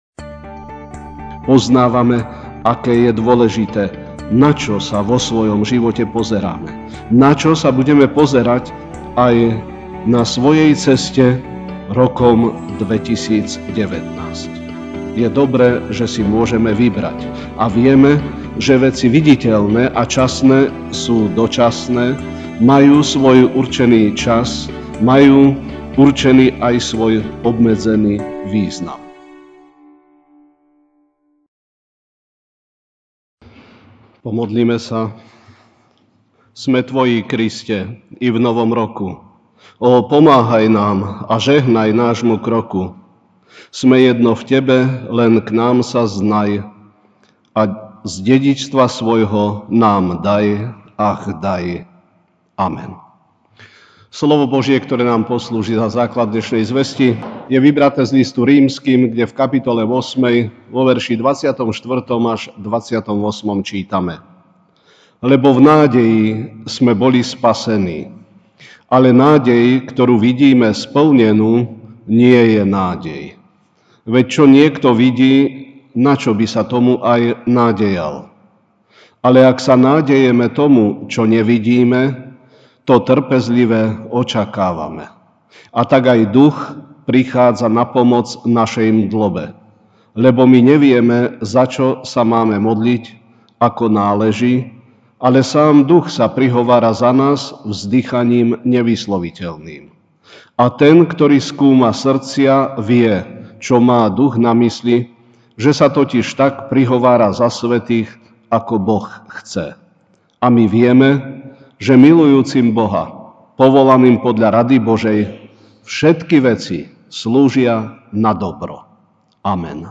jan 01, 2019 Nasledovanie Pána v novom roku MP3 SUBSCRIBE on iTunes(Podcast) Notes Sermons in this Series Večerná kázeň: Nasledovanie Pána v novom roku (R 8, 24-28) Lebo v nádeji sme boli spasení.